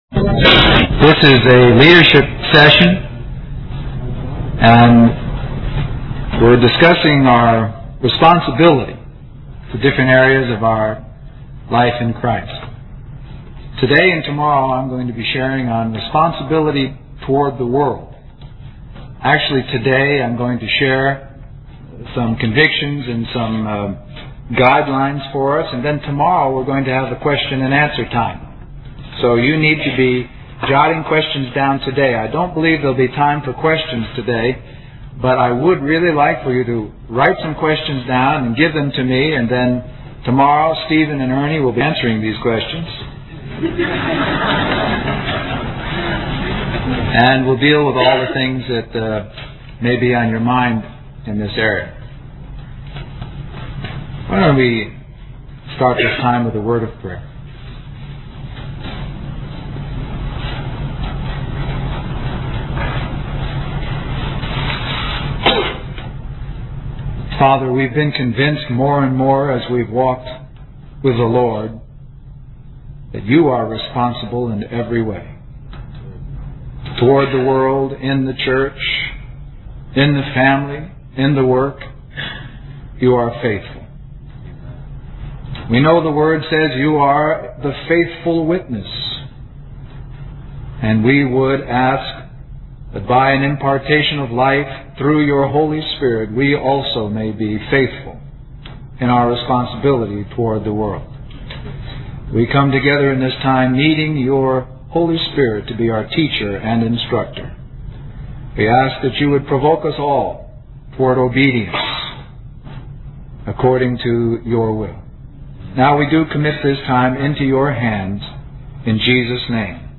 1982 Christian Family Conference Stream or download mp3 Summary In this leadership session